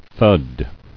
[thud]